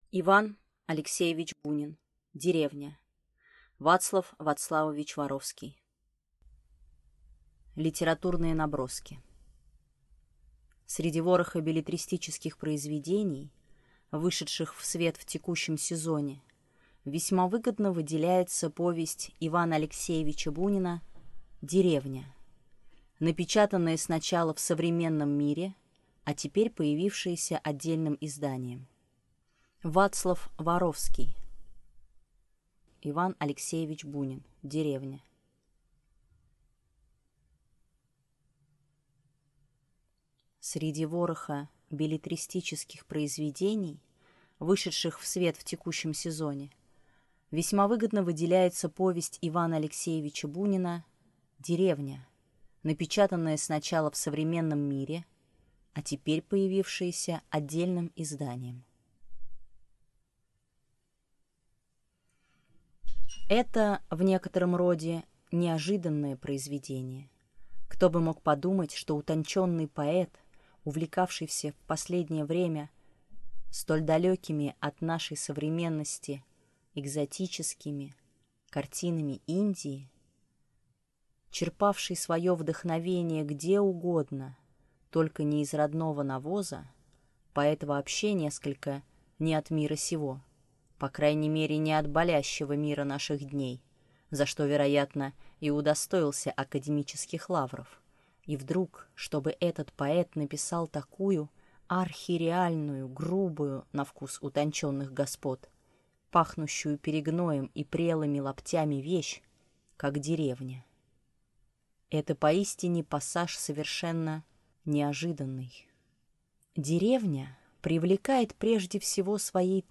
Аудиокнига И. А. Бунин. «Деревня» | Библиотека аудиокниг